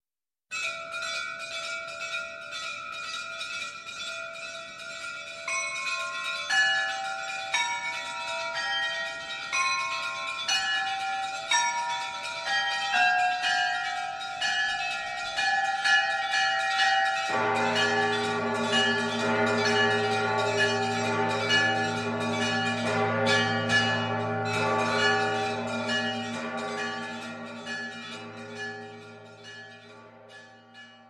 Замечательно с колокольным звоном!!!;))
Прекрасный вид и так хорошо с колокольным звоном!